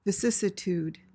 PRONUNCIATION:
(vi-SIS-i-tood/tyood)